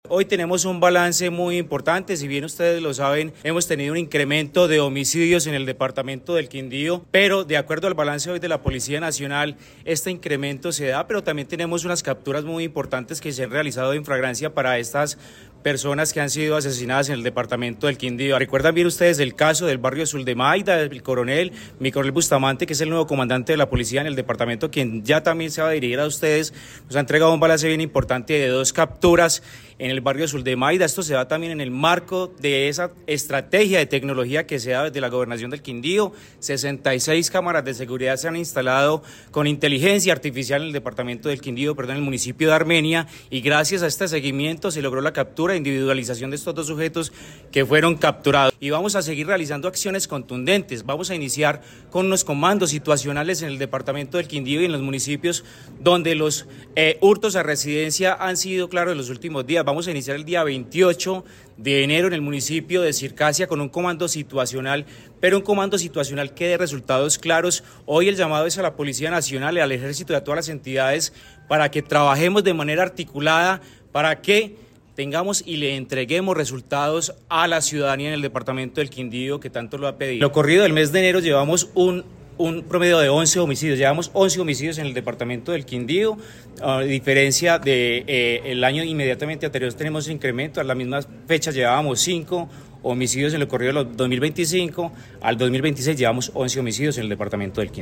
Secretario de Interior del Quindío, Diego Alexander Santamaría, consejo de seguridad extraordinario